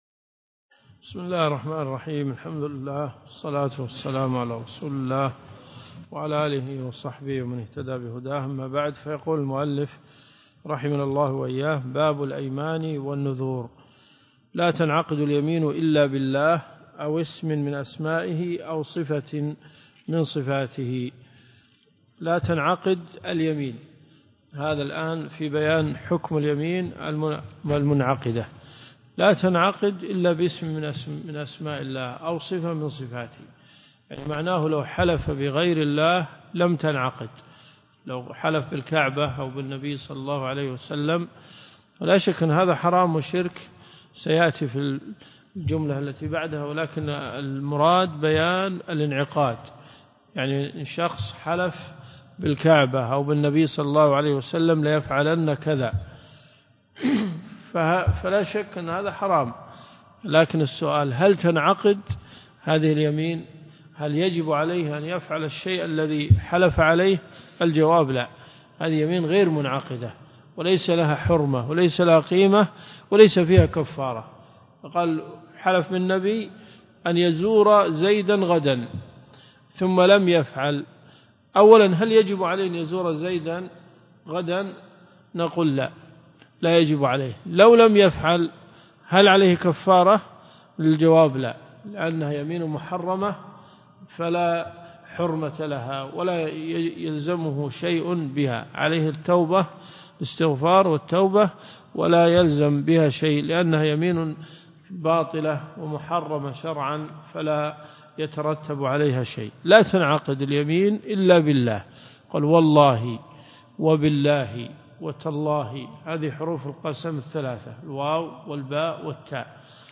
الدروس الشرعية
المدينة المنورة . جامع البلوي